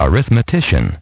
Транскрипция и произношение слова "arithmetician" в британском и американском вариантах.